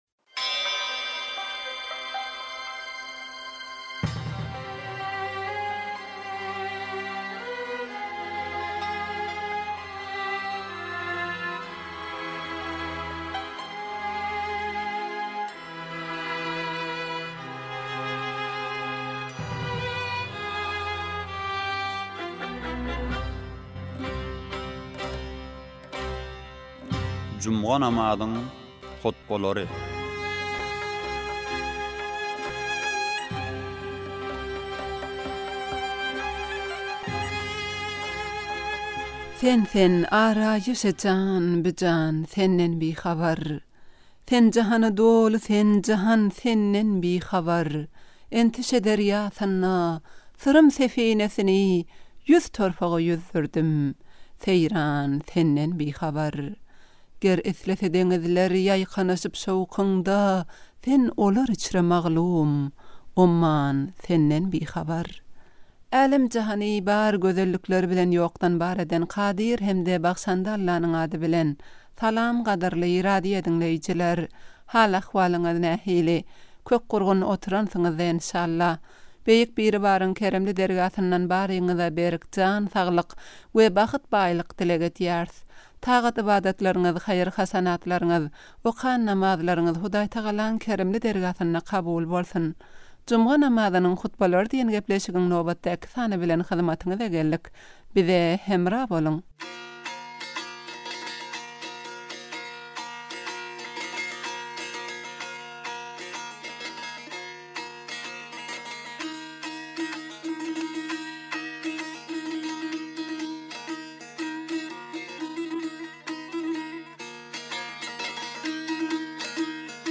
juma namazyň hutbalary
Tehraniň juma namazy